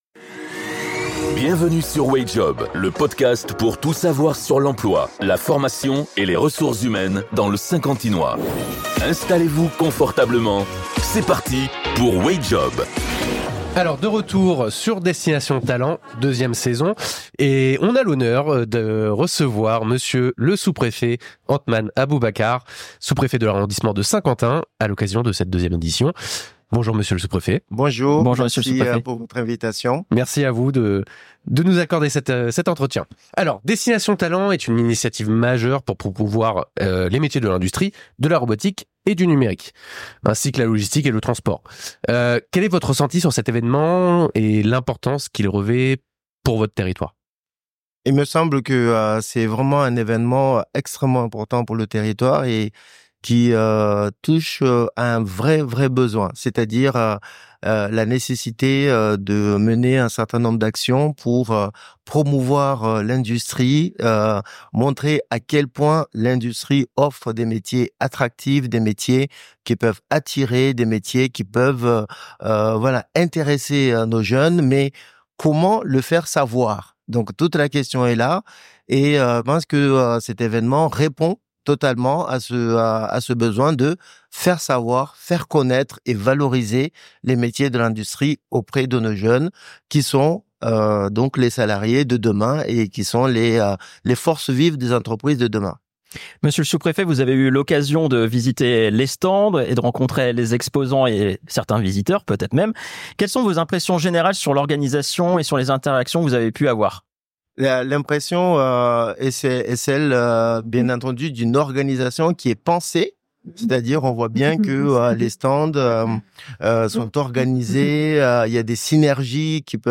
Episode 18 - Entretien avec Monsieur le Sous-Prefet de l'arrondissement de Saint-Quentin : Une vision pour l’industrie